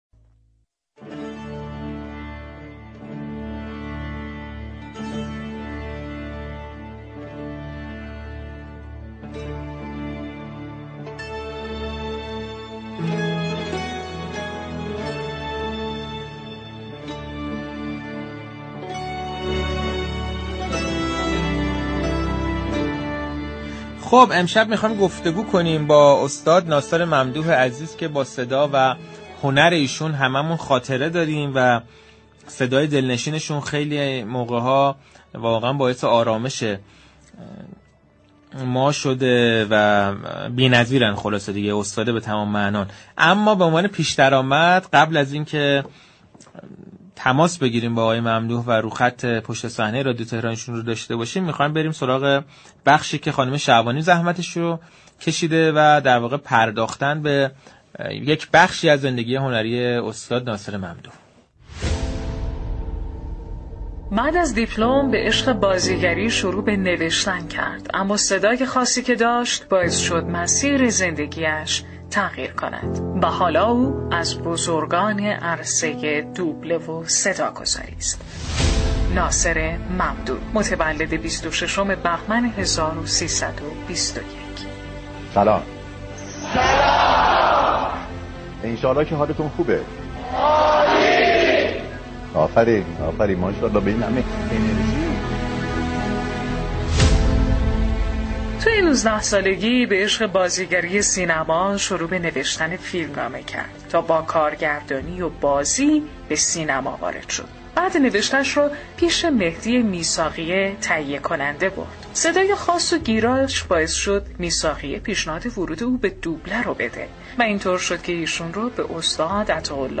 ناصر ممدوح در گفتگو با برنامه پشت صحنه رادیو تهران گفت: من دیگر نمی‌خواهم جلوی دوربین ظاهر شوم؛ اما این به معنی خداحافظی من از عرصه هنر نیست.